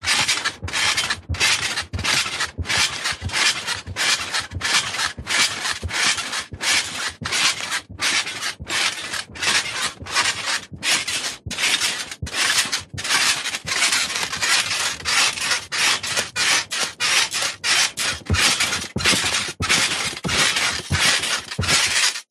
Звуки батута
На этой странице собраны звуки батута: прыжки, отскоки, скрип пружин и другие эффекты.
Звук прыжка на батуте, будто из мультика